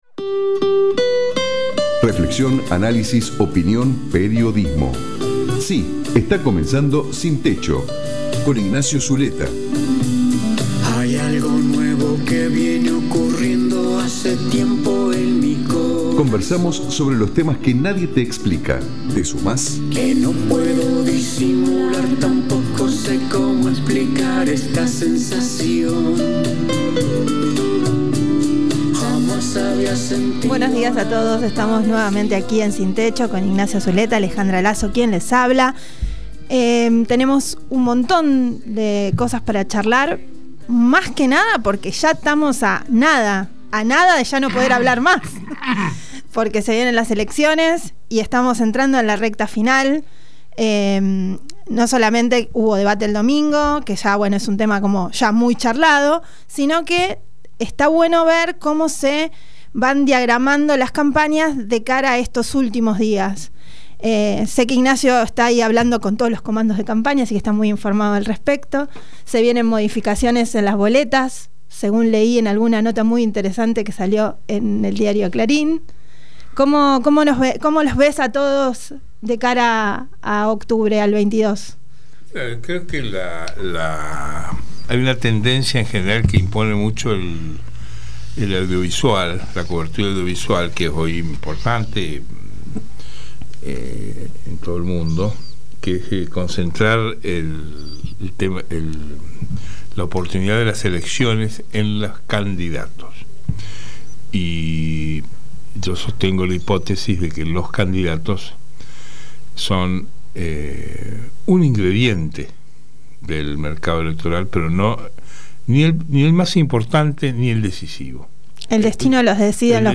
Comparto el contenido del programa Sin Techo que se emite por radio FM Cultura 97.9 MHz.